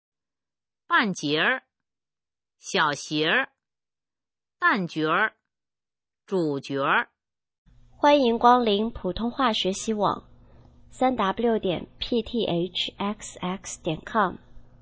普通话水平测试用儿化词语表示范读音第10部分